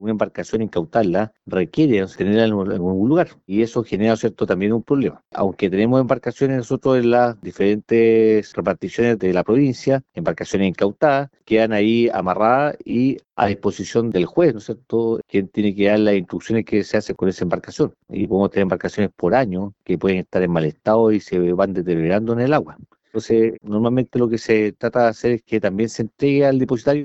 Según complementó el gobernador marítimo de Castro, capitán de navío Ricardo Cárcamo, se hacen seguimientos y operativos, pero -comentó- las condiciones reales se traducen en una menor capacidad de detención de personas (que quedan citadas a los Juzgados) a las que se aplican solo infracciones.